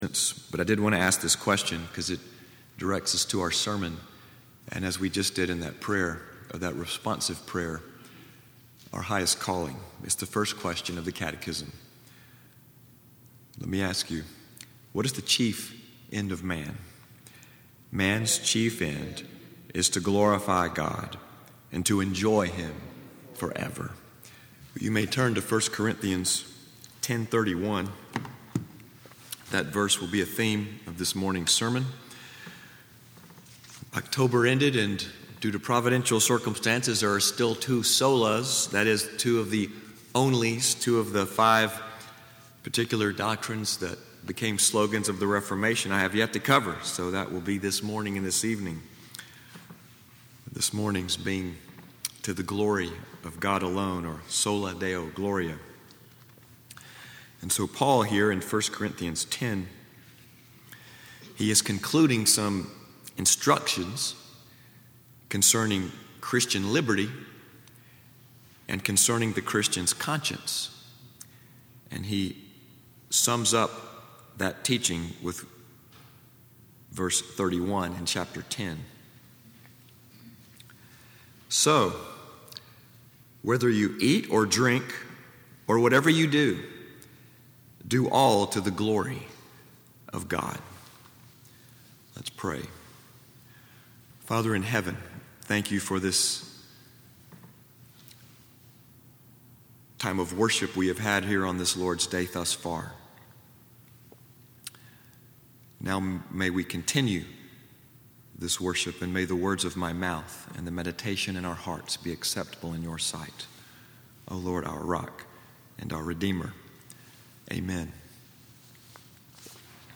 Sermon text: 1 Corinthians 10:31